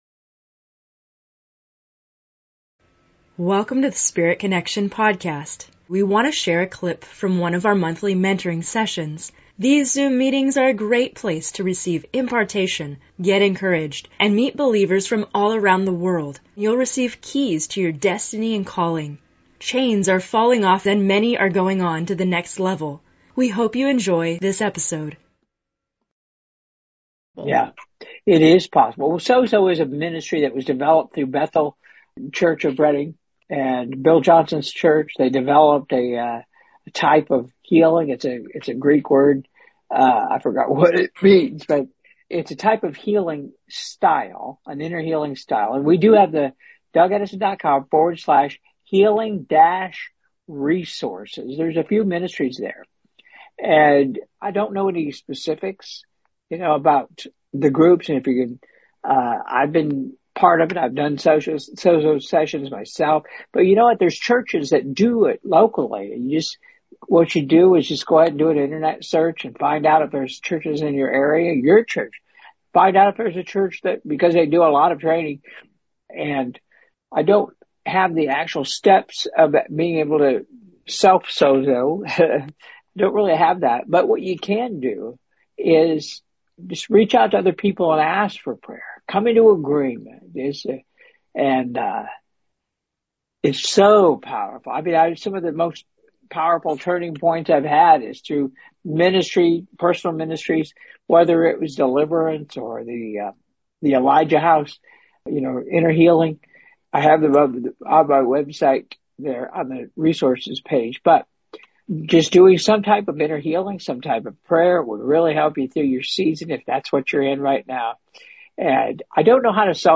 In this episode of Spirit Connection, we have a special excerpt from a Q&A session in a recent Monthly Mentoring Session.